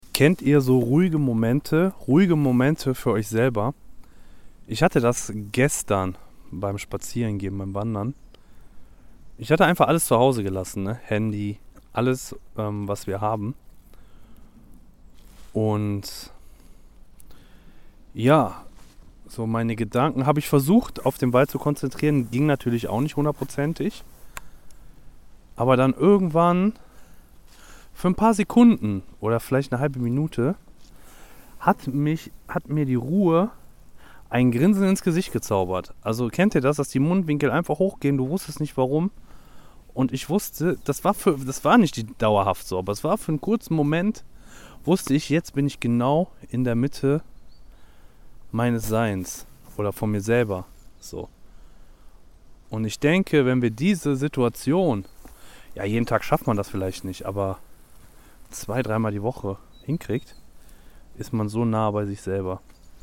Ein Gedanke aus Energie ohne Grund – leise, warm